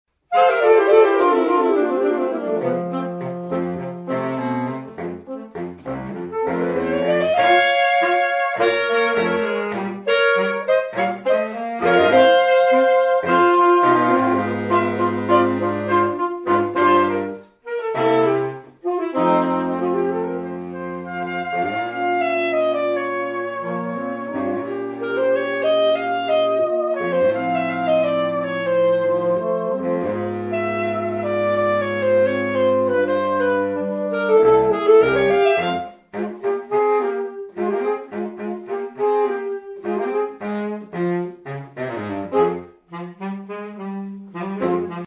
Tango
4 Saxophone (AATBar)